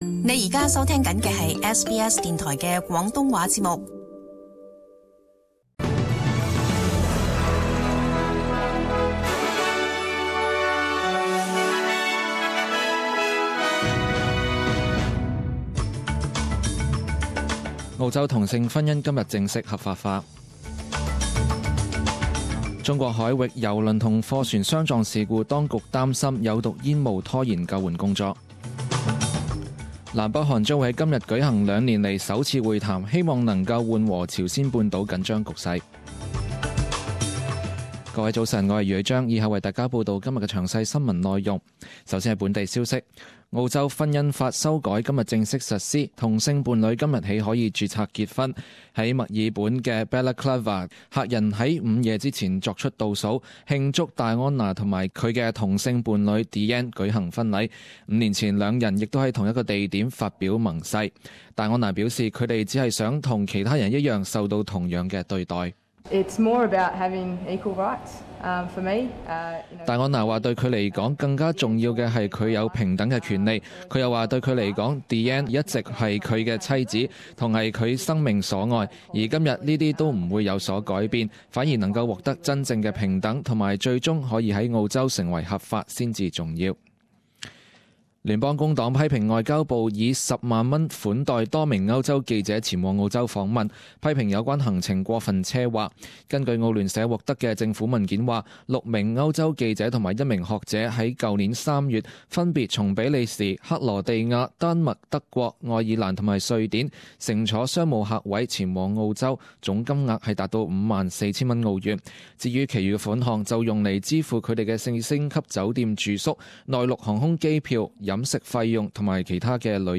十点钟新闻报导 （一月九日）
SBS Cantonese 10am news Source: SBS